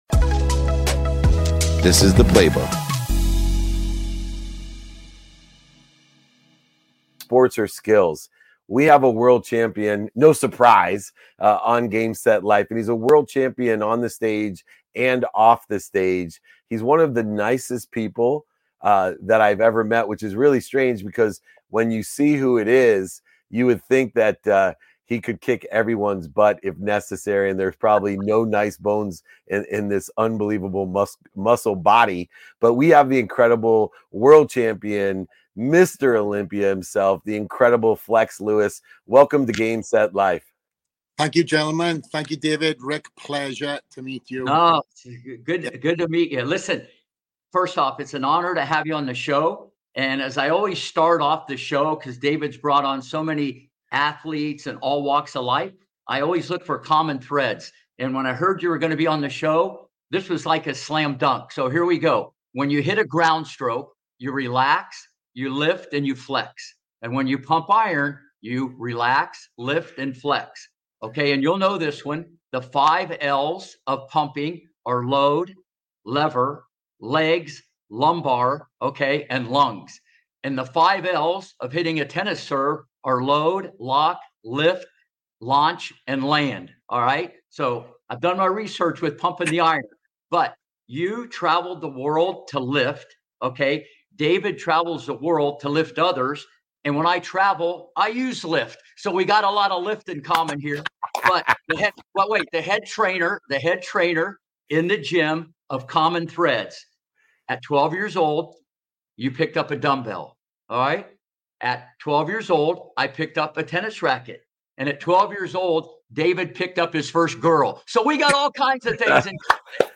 In the 10th episode of Game, Set, Life with legendary tennis coach Rick Macci, we are joined by 7x Mr.Olympia winner, Flex Lewis. We dive into the unique backstory behind Lewis' bodybuilder nickname and learn about the key factors that contributed to his unprecedented 7-year winning streak. Lewis shares valuable insights on how his experiences in bodybuilding have influenced his business ventures, providing you with his blueprint for success.